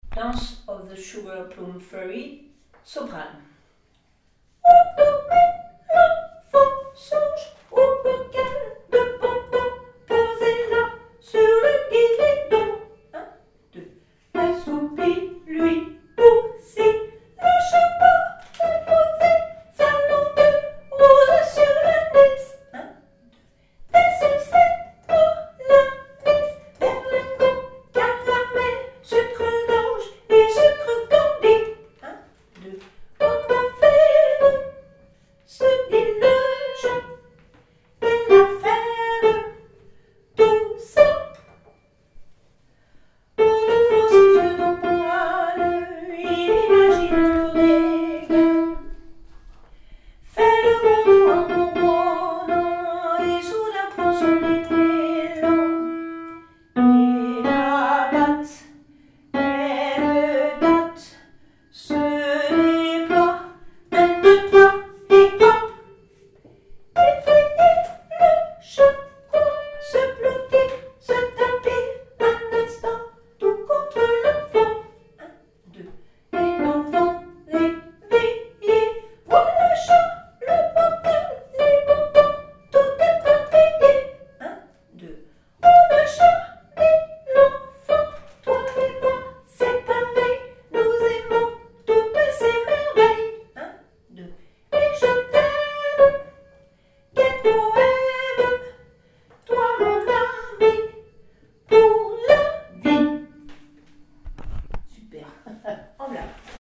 Enregistrement SOPRANO